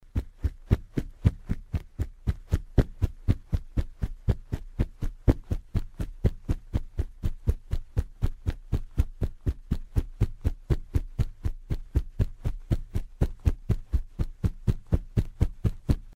Sounds of a Hippo (Hippopotamus) download and listen online